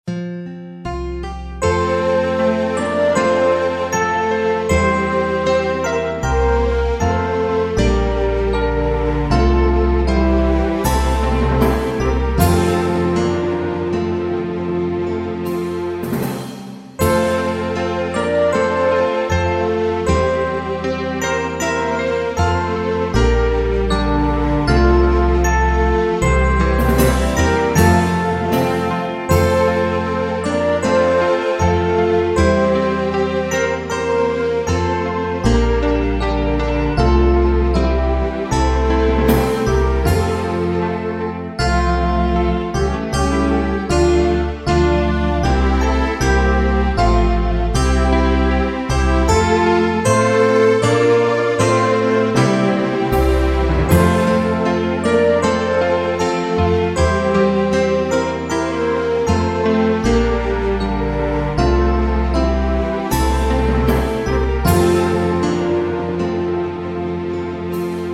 Ende Partangiangan